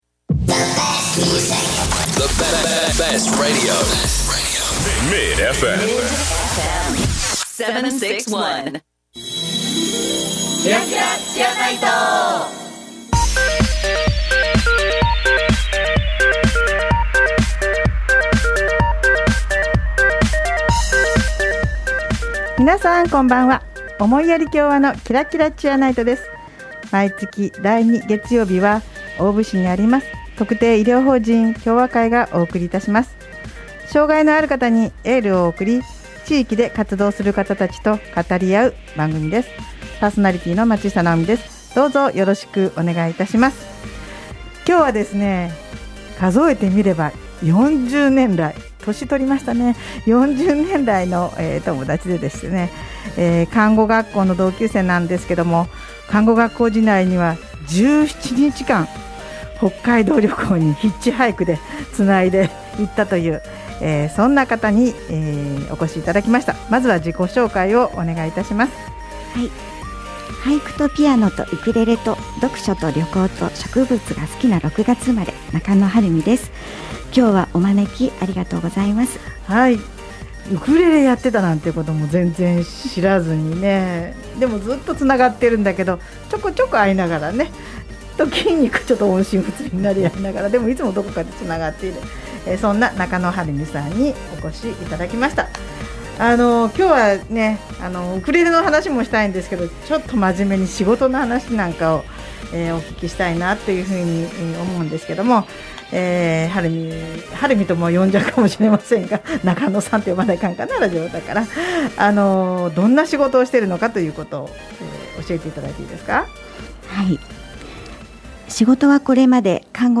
【放送時間 】第2月曜日 19：00 MID-FM 76.1 【ゲスト】
この番組では、地域の医療・福祉に携わる方々と語り合い、偏見にさらされやすい障がいのある方に心からのエールを送ります。 毎回、医療・福祉の現場に直接携わる方などをゲストに迎え、現場での色々な取り組みや将来の夢なども語り合います。